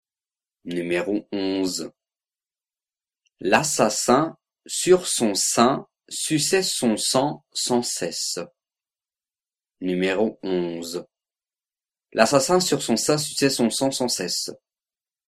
11 Virelangue